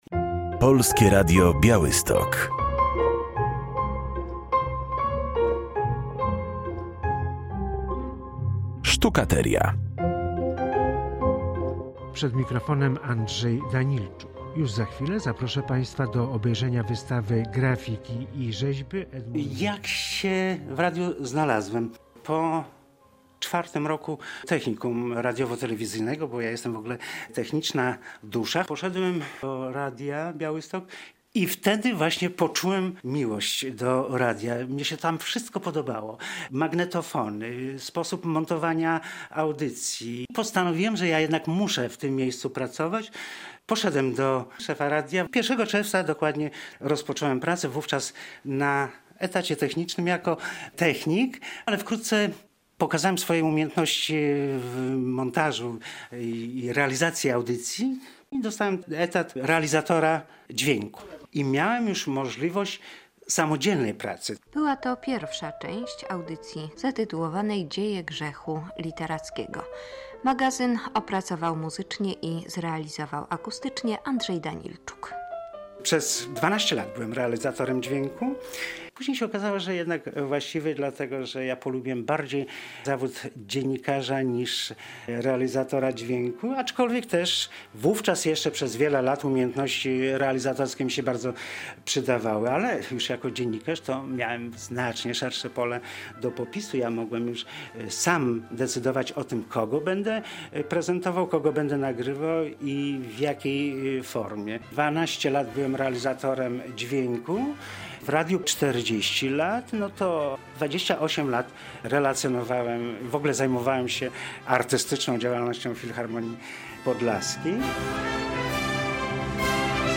Fragment reportażu